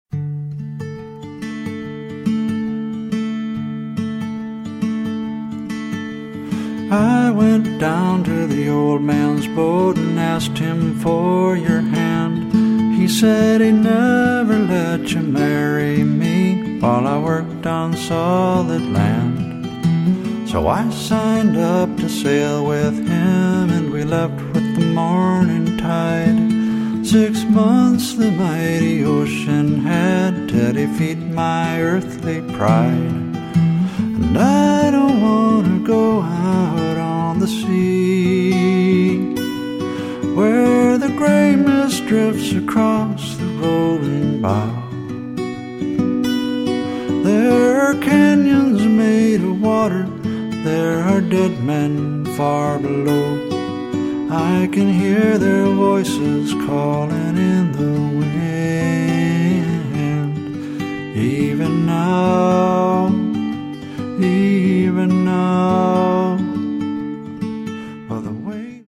folk music
Recorded at David Lange Studios